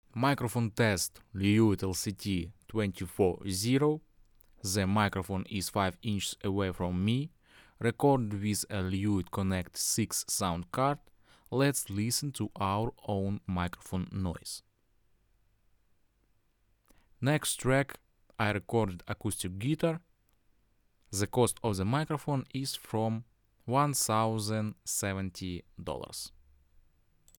In the test I recorded voice and acoustic guitar in different card modes.
Lewitt Connect 6 sound card + Lewitt 240 Pro microphone:
🗣 Voice recording:
As you can hear from the examples, the sound is clear, there is no noise or distortion.